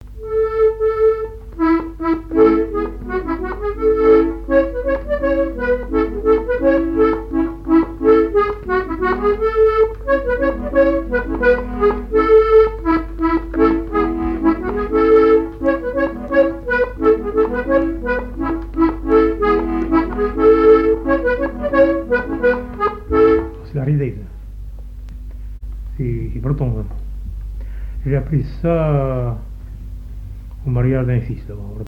Mémoires et Patrimoines vivants - RaddO est une base de données d'archives iconographiques et sonores.
danse : laridé, ridée
Pièce musicale inédite